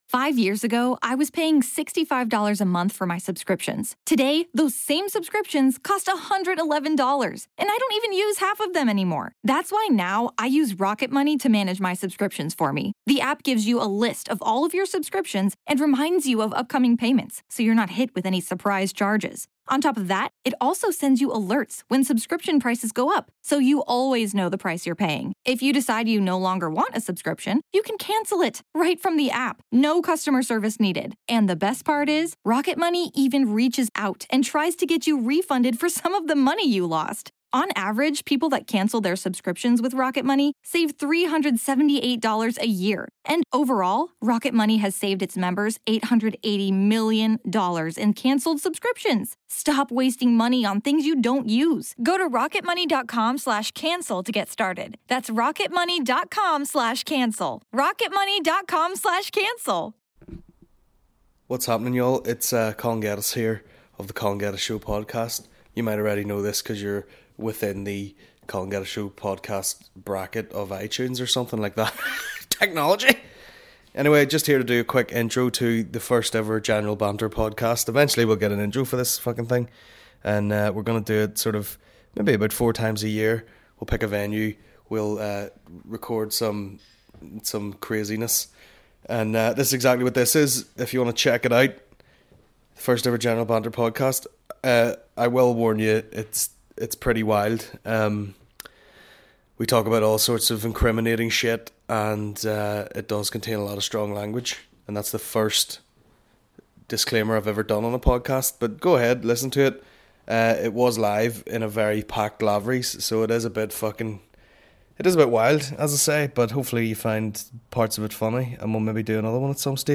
General Banter Podcast General Banter Podcast Comedy 4.8 • 1.1K Ratings 🗓 17 June 2015 ⏱ 46 minutes 🔗 Recording | iTunes | RSS 🧾 Download transcript Summary The first ever General Banter Live Podcast recorded at Lavery's Comedy Club.